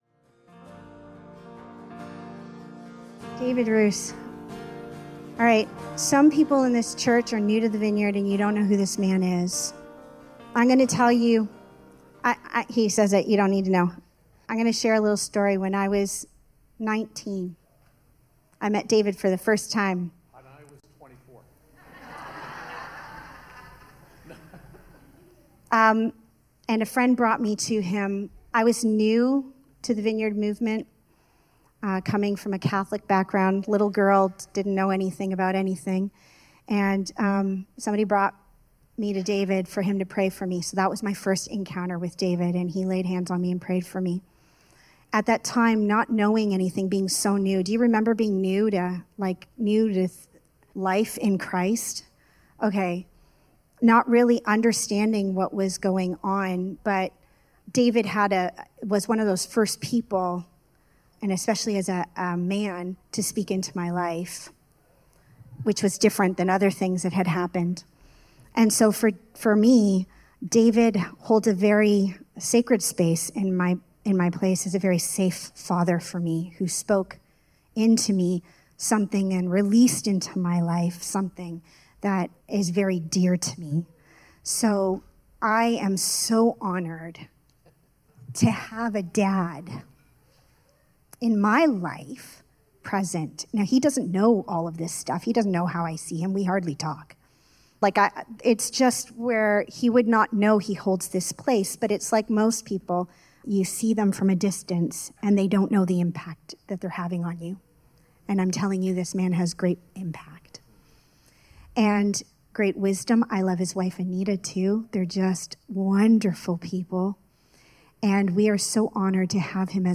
Series: Guest Speaker Service Type: Sunday Morning